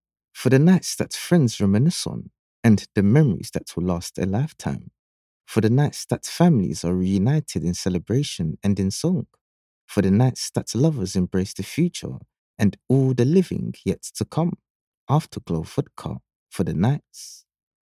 Male Voice Over Talent, Artists & Actors
English (Caribbean)
Yng Adult (18-29) | Adult (30-50)